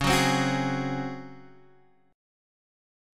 DbM7sus4 chord